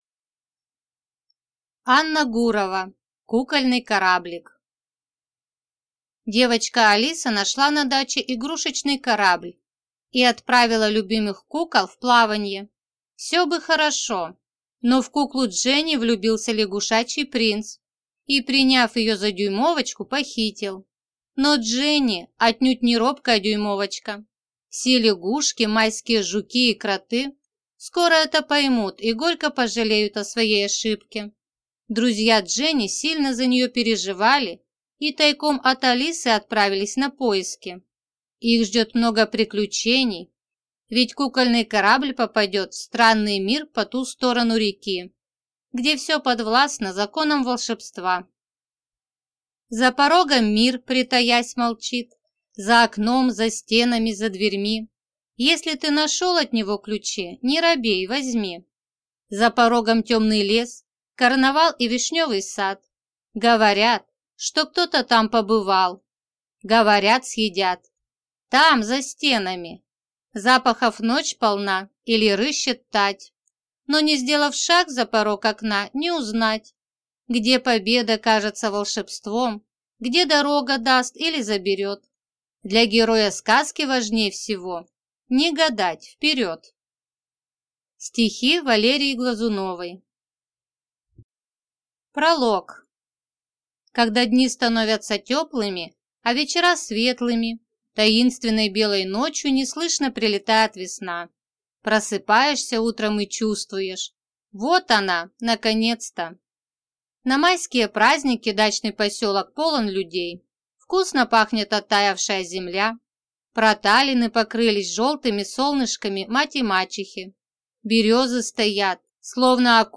Аудиокнига Кукольный кораблик | Библиотека аудиокниг